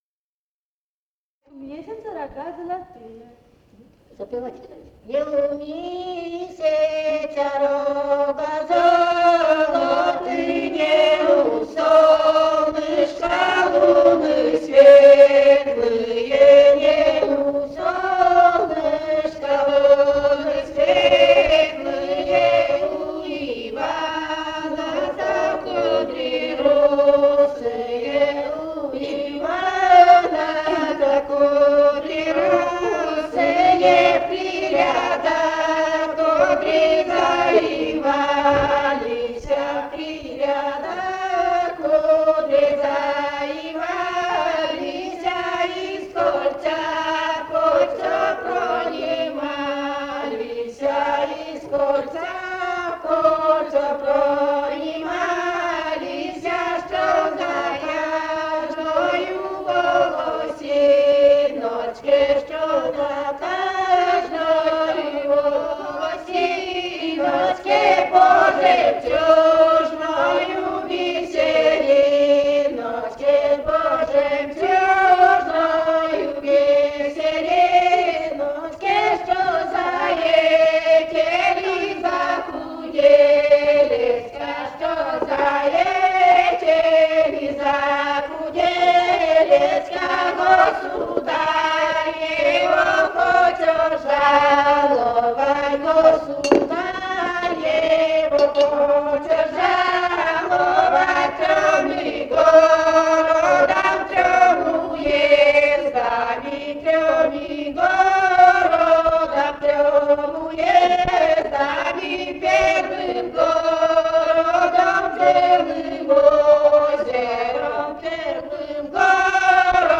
Живые голоса прошлого [[Описание файла::037б. «Не у месяца рога золоты» (свадебная).